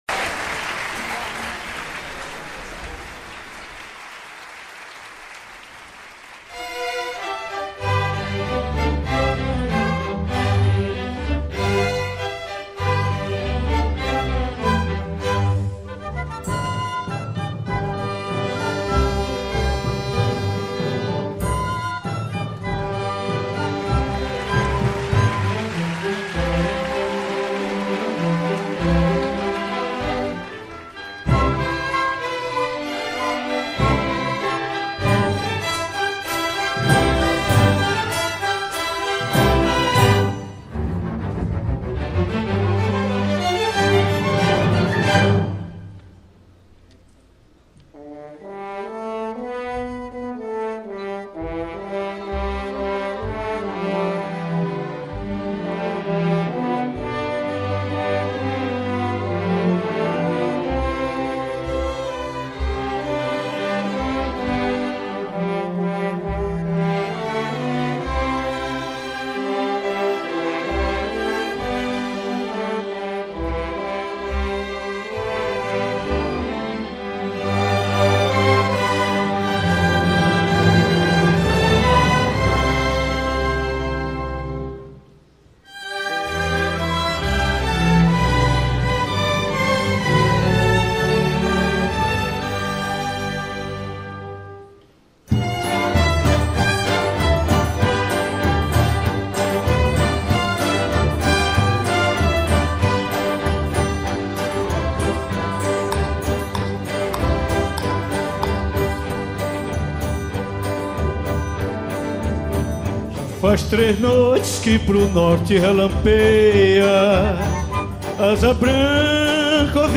07:10:00   Mpb